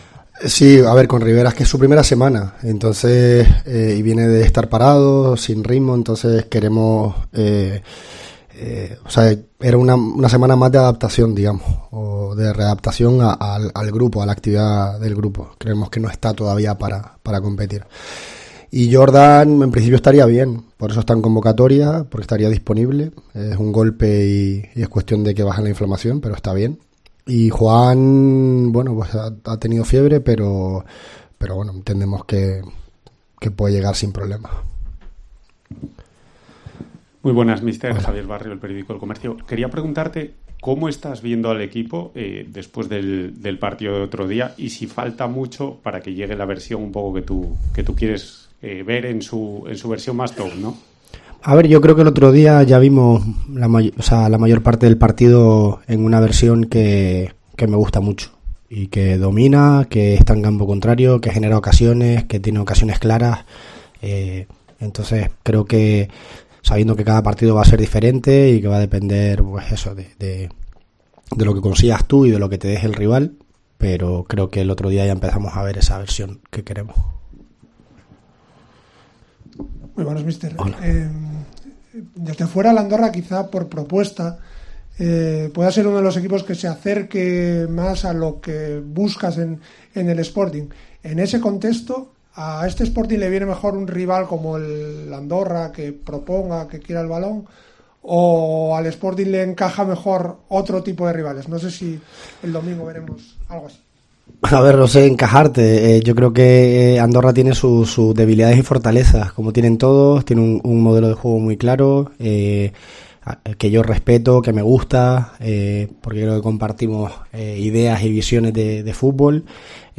El técnico ha dado la rueda de prensa previa al partido este viernes en Mareo.